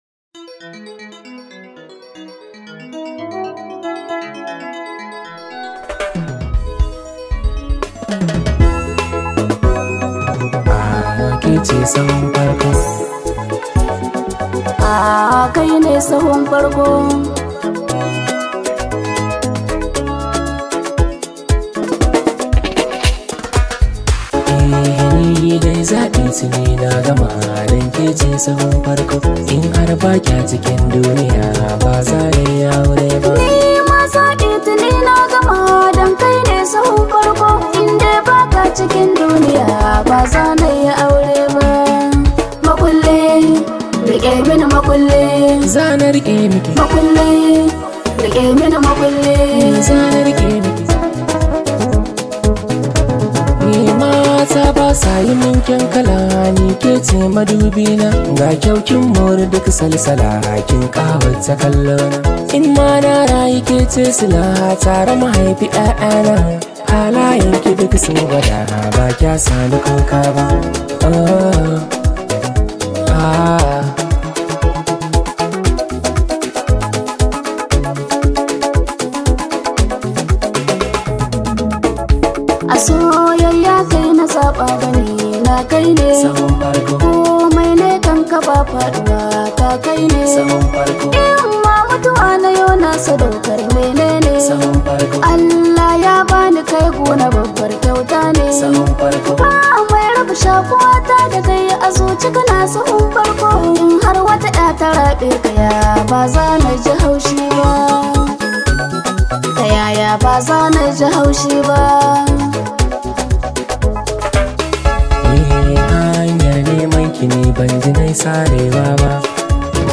This high vibe hausa song